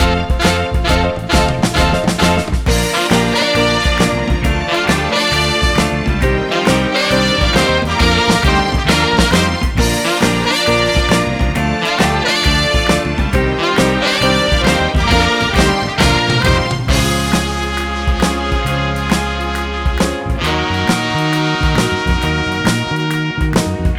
no Backing Vocals Soul / Motown 2:46 Buy £1.50